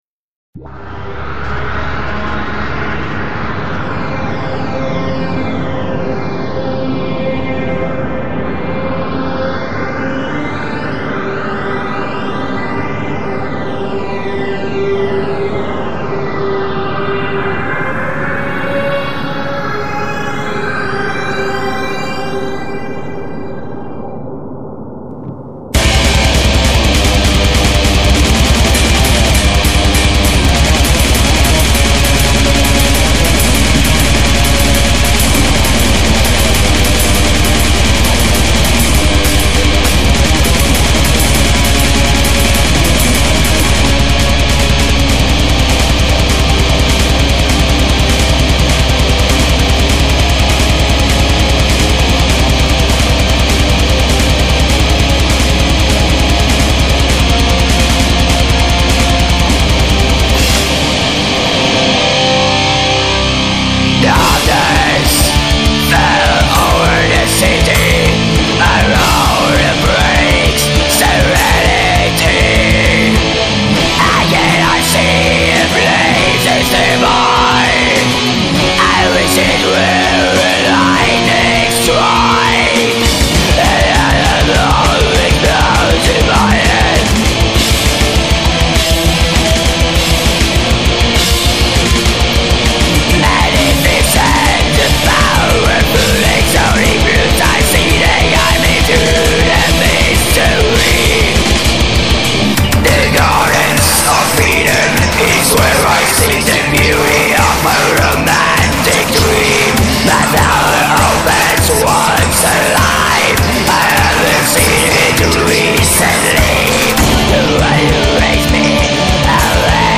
Industrial black metal.